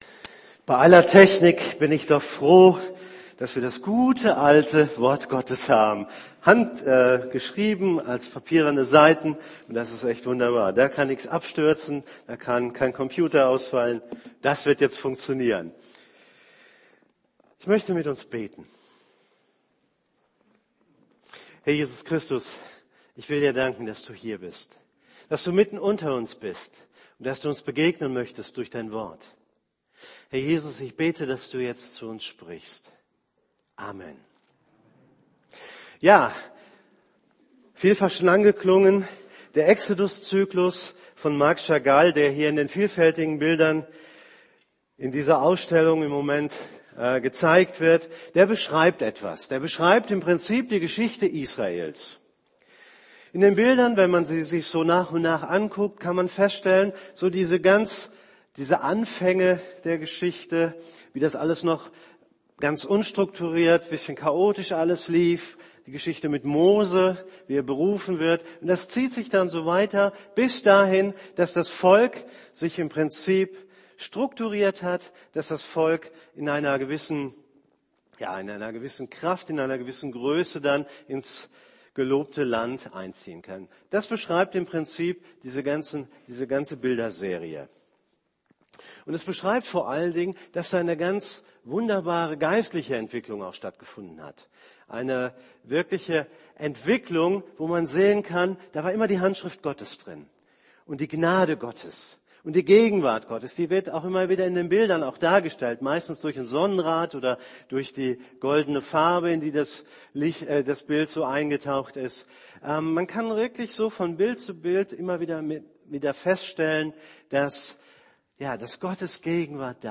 predigt.mp3